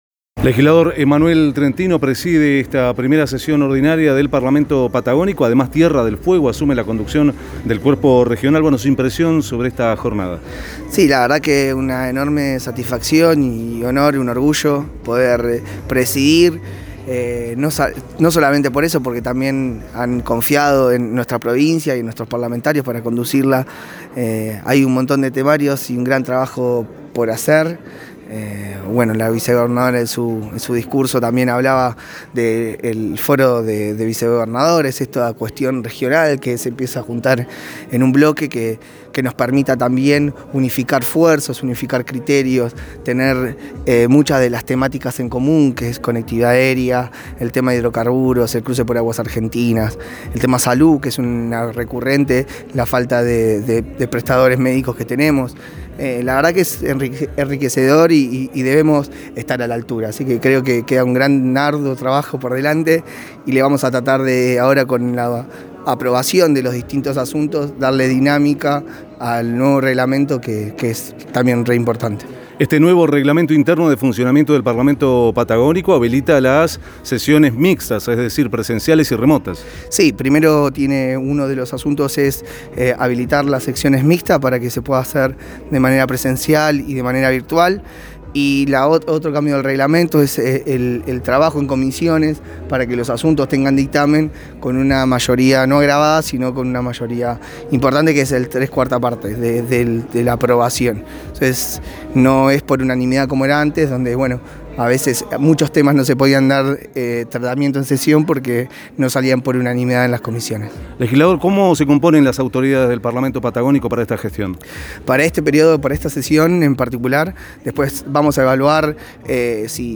1ª Sesión del Parlamento Patagónico declaraciones de los legisladores